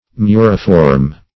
Search Result for " muriform" : The Collaborative International Dictionary of English v.0.48: Muriform \Mu"ri*form\, a. [L. murus a wall + -form.]
muriform.mp3